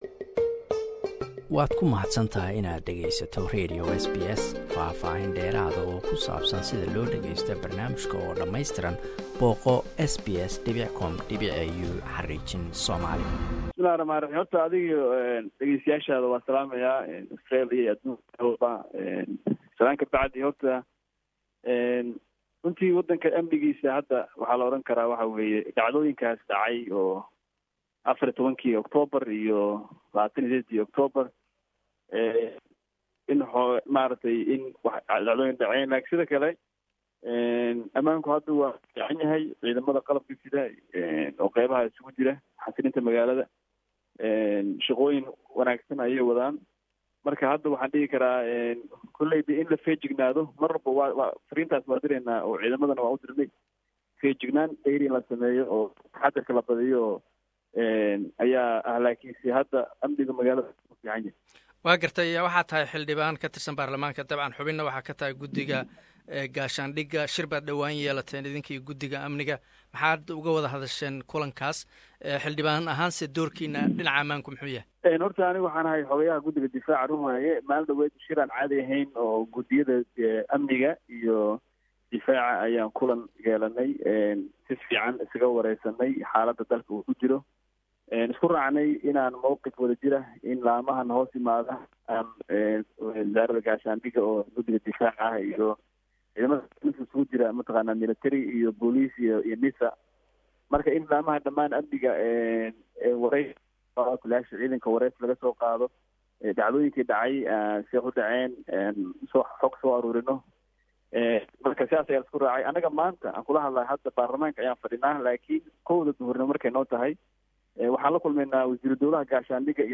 Interview with Sadiq Warfaa, a Somali member of parliament.